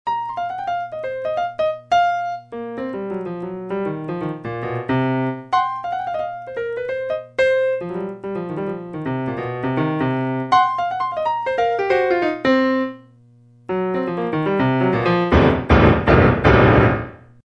My latest attempt to remedy this situation is a training exercise I call "piano simon". I play a lick in the right hand, and then clumsily attempt to mimic it two octaves lower.
My right hand instinctively inserts grace notes and trills wherever possible and flows through them effortlessly; my left hand thuds out these ornamentals with all the grace of an offensive lineman trying out for the ballet.